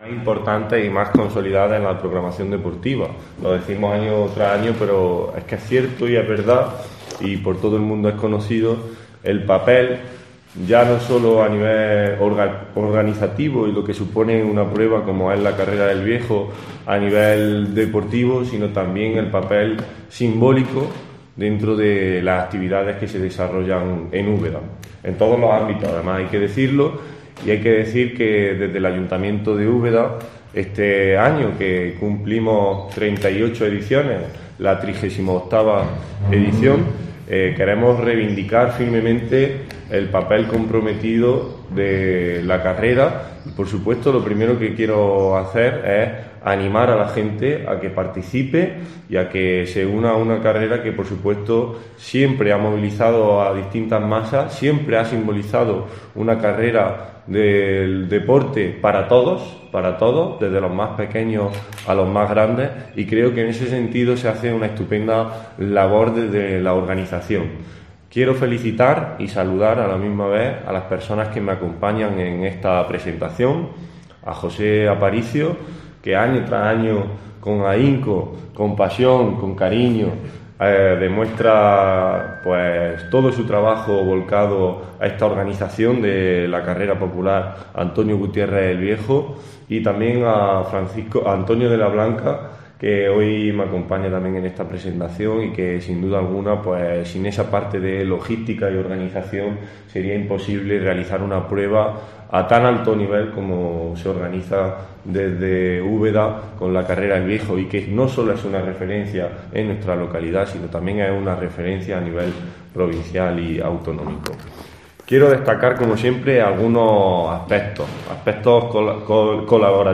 Francisco Javier Lozano, concejal de Deportes sobre la XXXVIII Carrera Popular Antonio Gutiérrez ‘El Viejo’